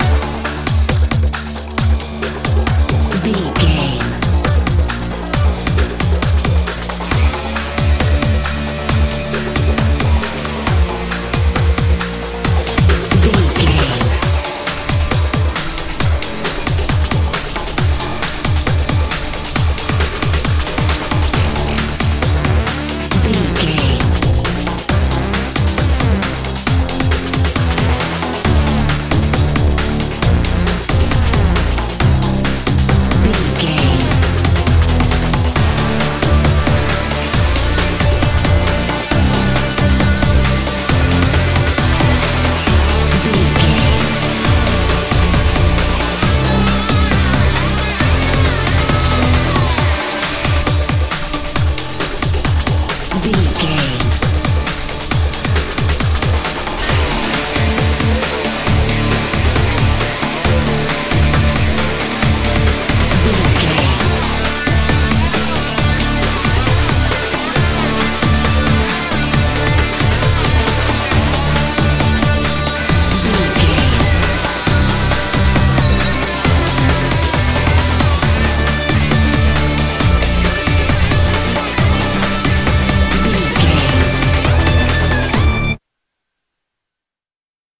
Aeolian/Minor
drum machine
synthesiser
pop rock
hard rock
lead guitar
bass
drums
aggressive
energetic
intense
powerful
nu metal
alternative metal